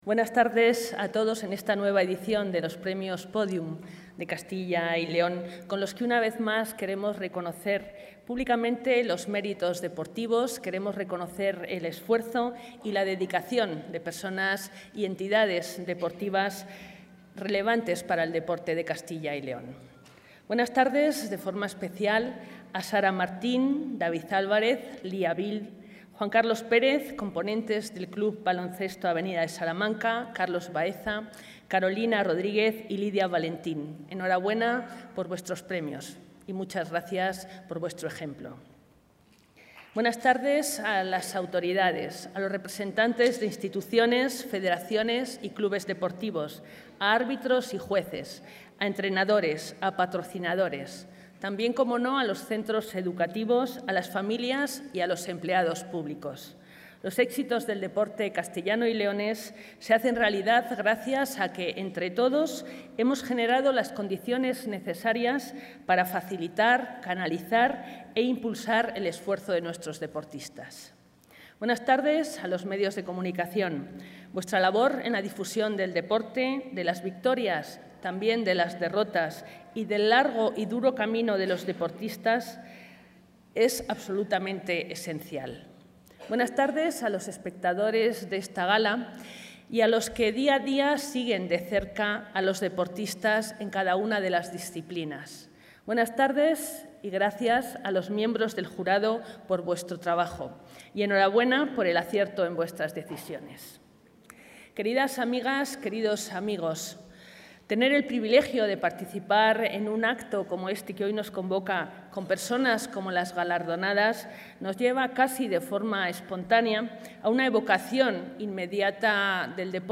Audio consejera.